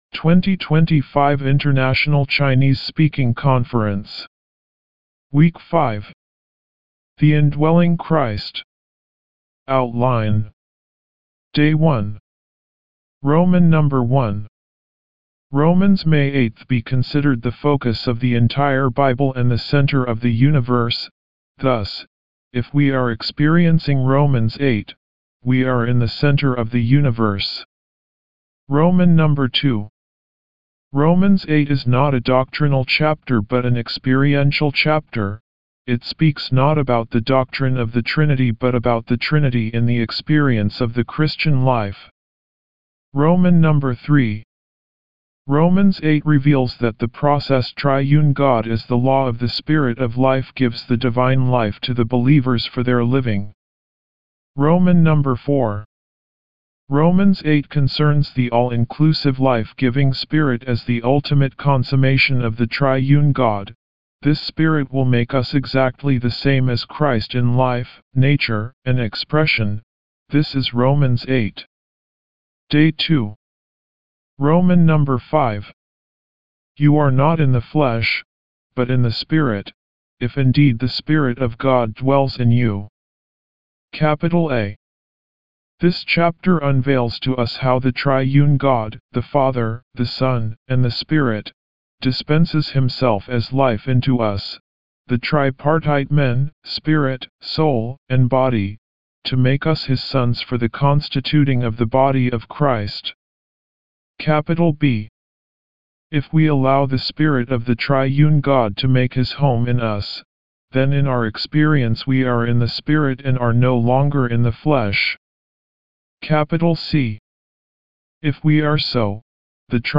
Morning Revival Recitation